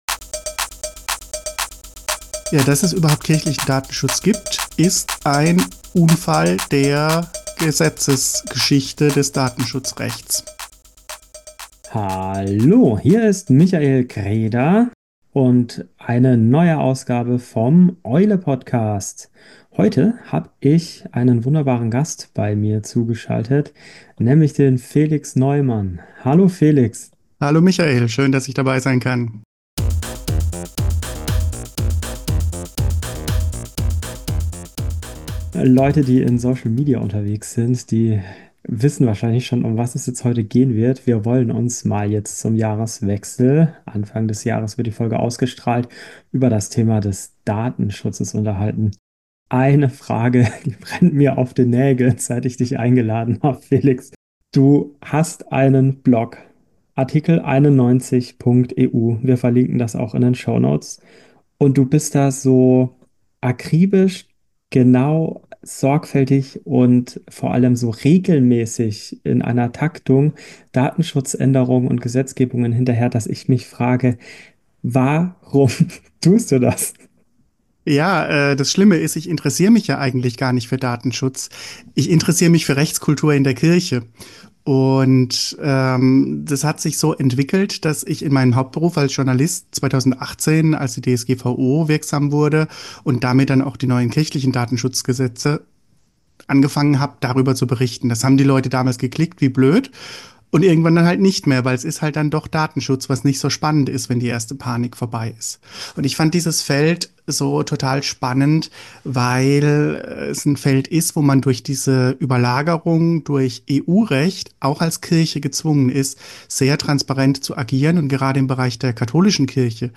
Die beiden sprechen darüber, ob man nun in der Kirche WhatsApp nutzen darf und welche Schwierigkeiten es bei der Umsetzung von Datenschutzregeln in Kirche und Gesellschaft gibt.